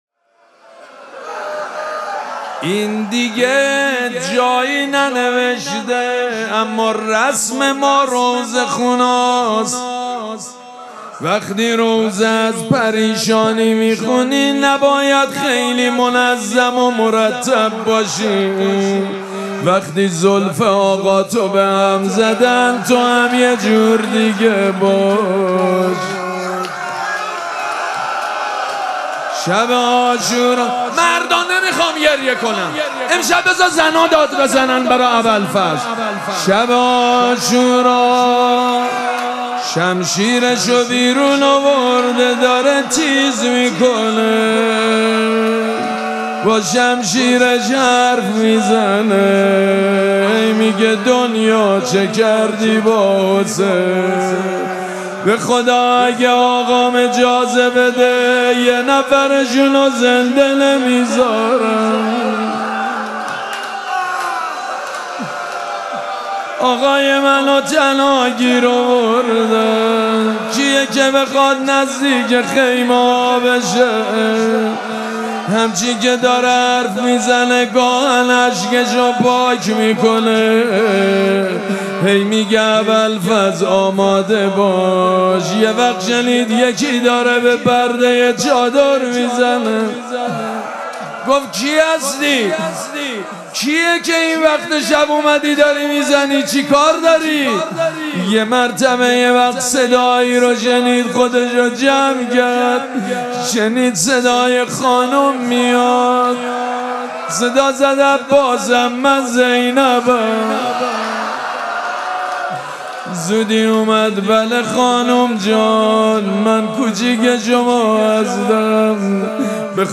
مراسم مناجات شب بیست و یکم ماه مبارک رمضان
روضه